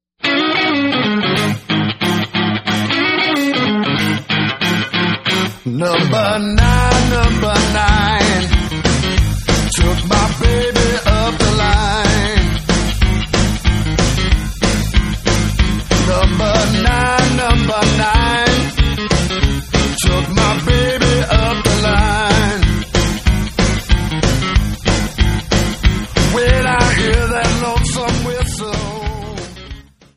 Classic, powerful, real, blues/rock power grooves.
Blues
Rock